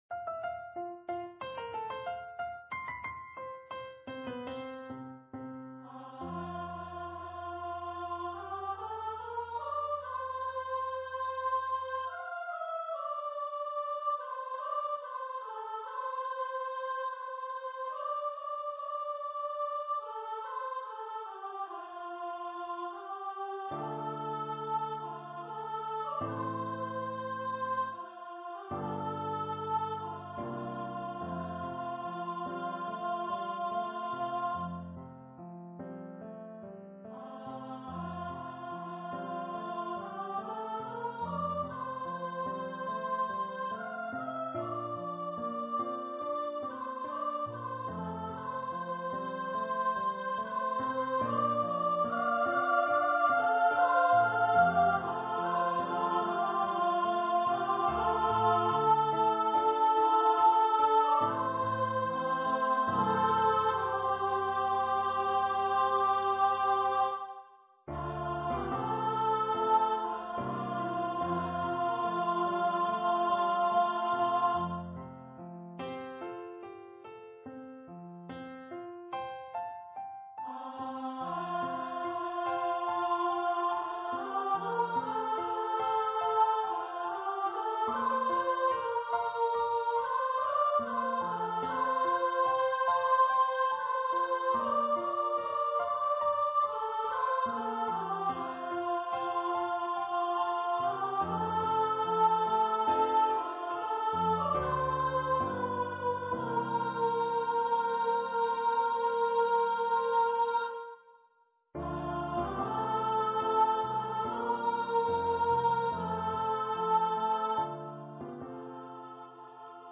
a sequence of five sea songs
for female voice choir and piano
Choir - 2 part upper voices